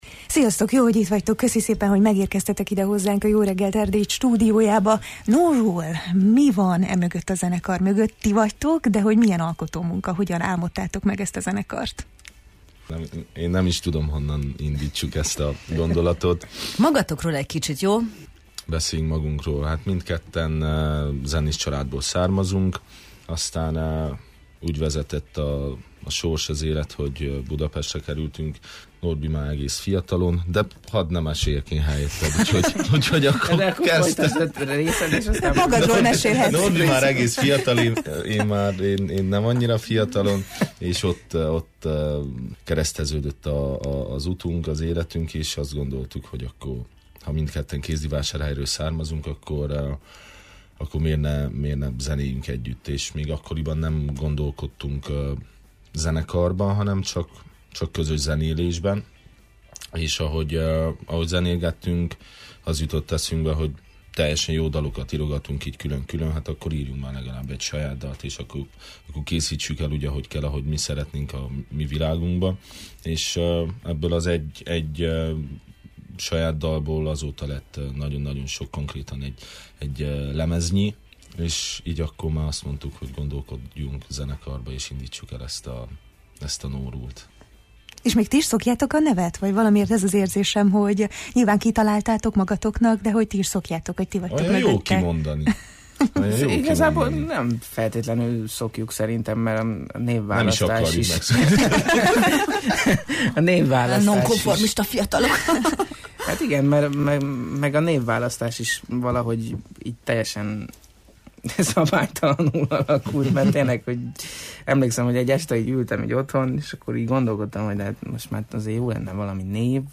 beszélgettünk a Jó reggelt, Erdély!-ben: